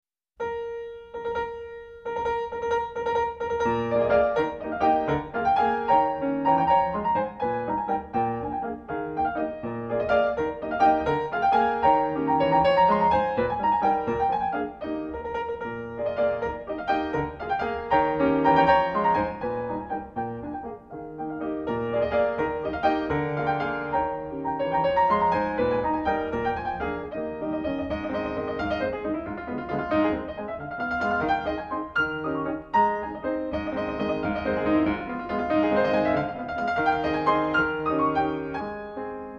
Pianist
refined, patrician touch
E flat major